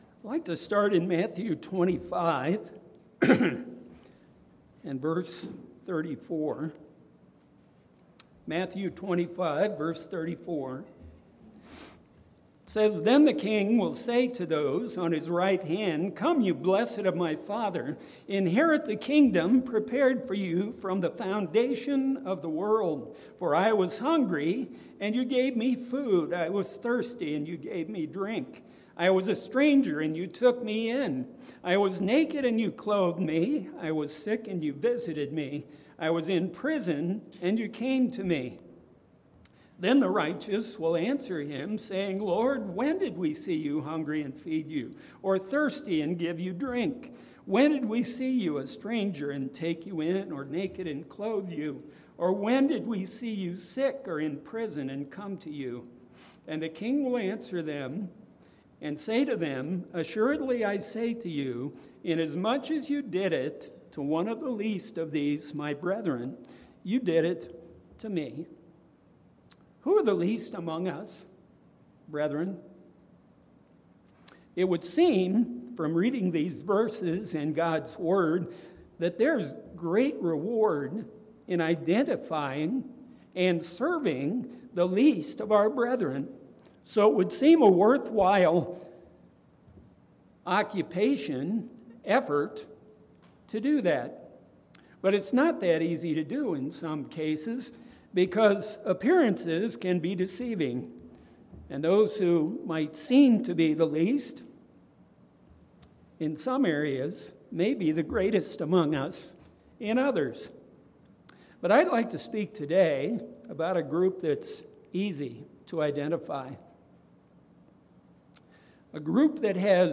Given in Mansfield, OH